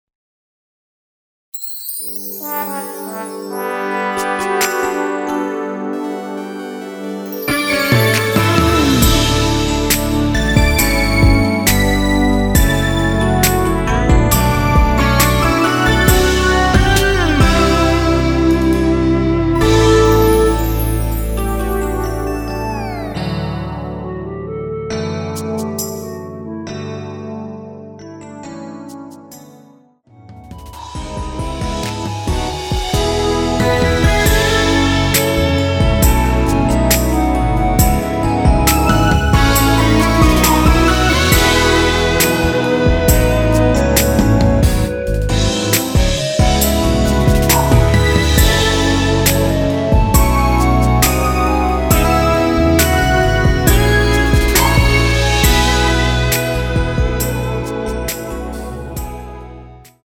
원키에서(-5)내린 멜로디 포함된 MR입니다.
앞부분30초, 뒷부분30초씩 편집해서 올려 드리고 있습니다.
중간에 음이 끈어지고 다시 나오는 이유는